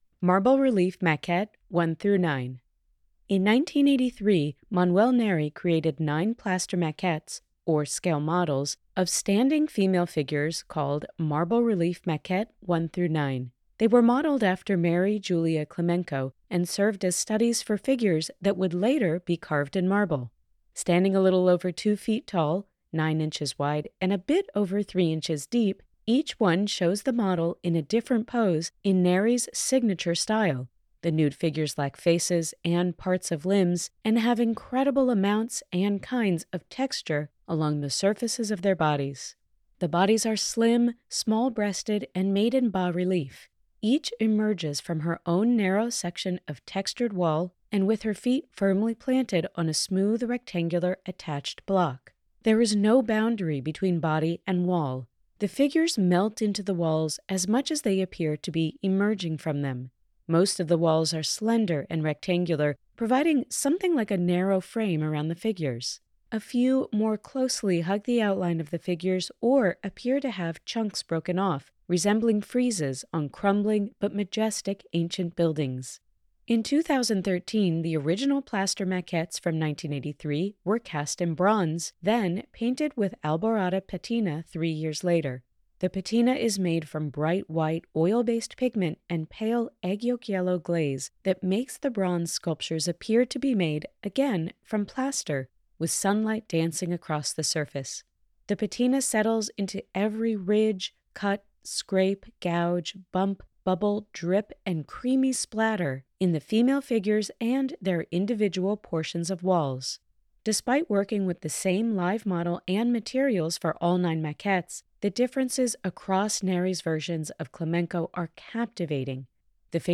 Audio Description (02:36)